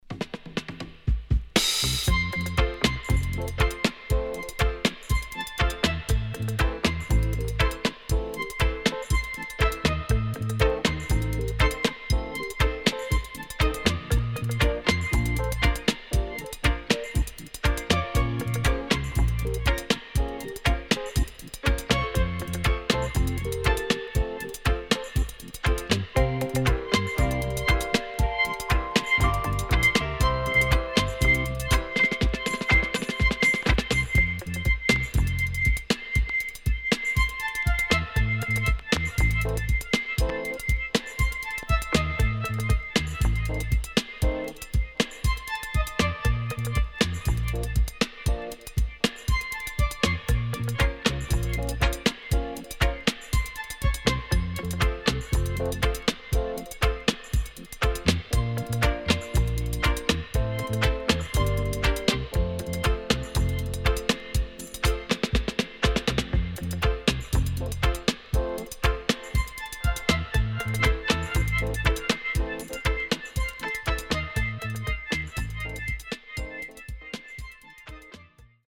【12inch】
SIDE A:うすいこまかい傷ありますがノイズあまり目立ちません。